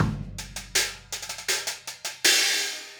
Hi Hat and Kick 01.wav